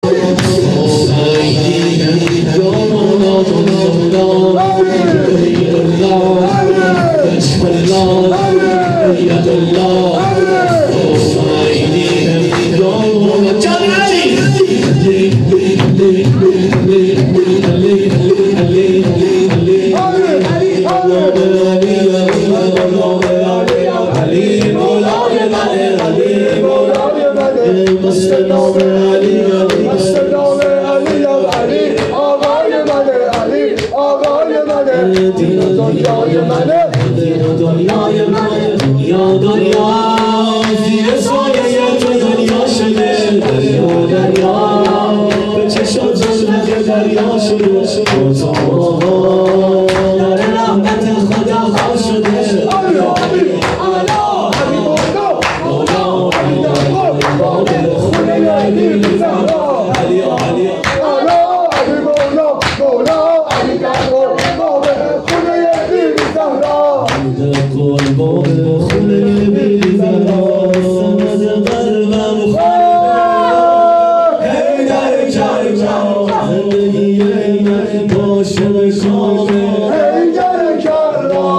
مراسم هفتگی۹۳/۱۱/۱۵
شور2